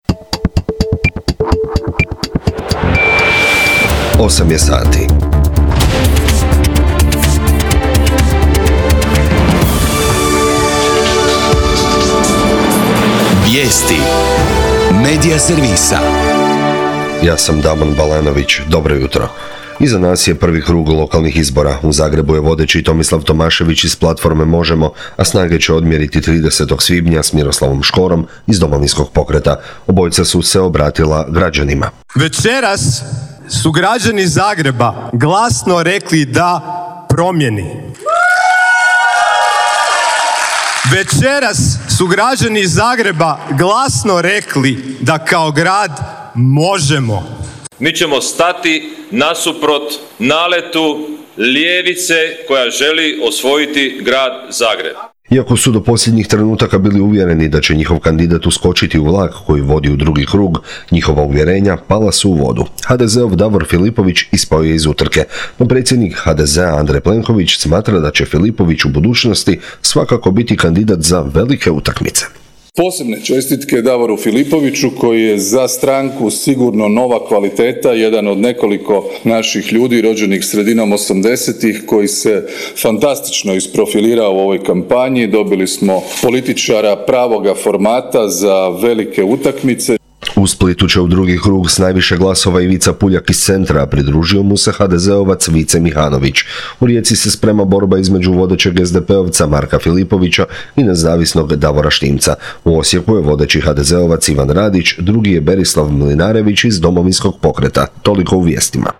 VIJESTI U 8